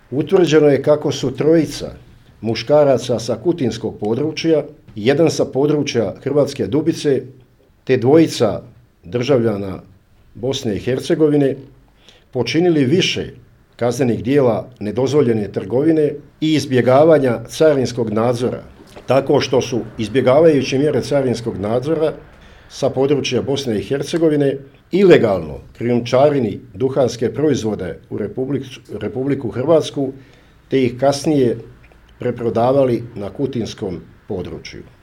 O tome je danas na tiskovnoj konferenciji u Sisku izvjestio načelnik PU sisačko-moslavačke Luka Pešut